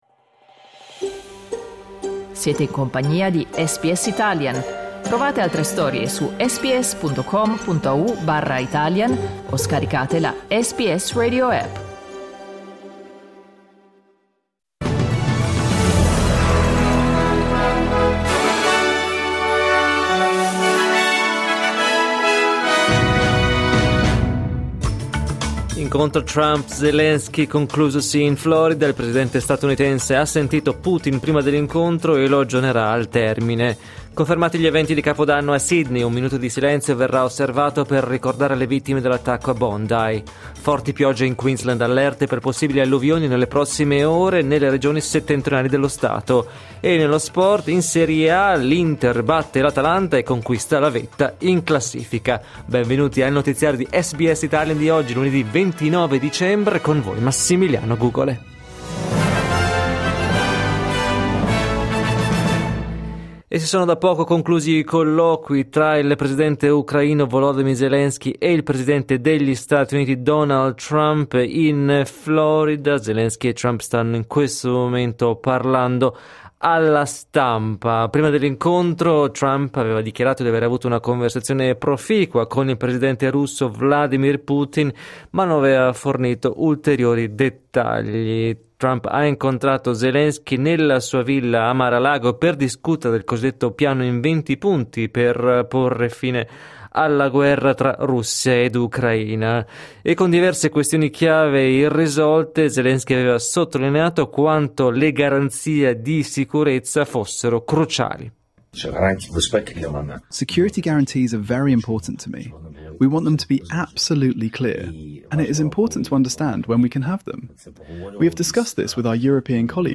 1 Giornale radio lunedì 29 dicembre 2025 9:55 Play Pause 36m ago 9:55 Play Pause Toista myöhemmin Toista myöhemmin Listat Tykkää Tykätty 9:55 Il notiziario di SBS in italiano.